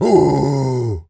Animal_Impersonations
gorilla_angry_03.wav